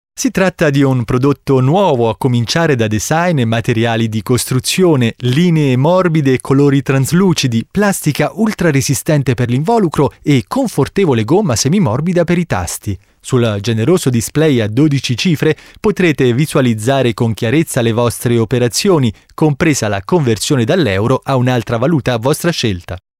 Sprecher italienisch.
Kein Dialekt
Sprechprobe: Werbung (Muttersprache):